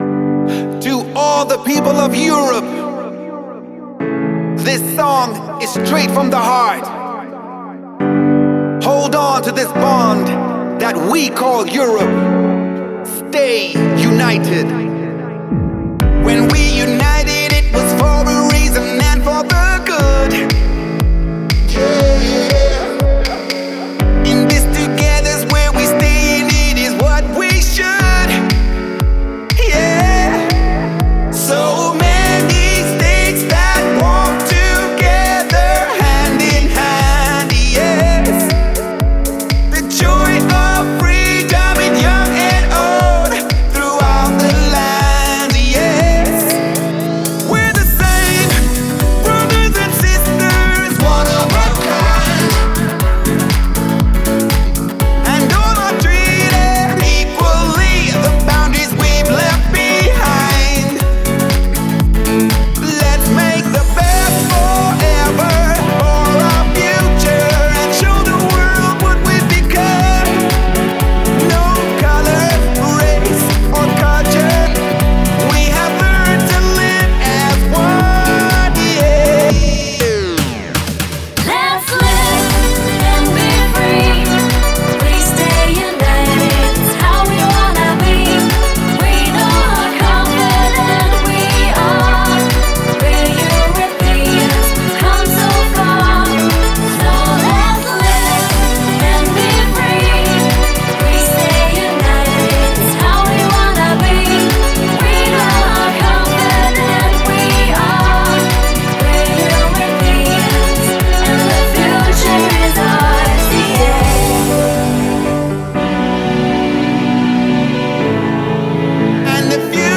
это яркая и энергичная композиция в жанре евро-дэнс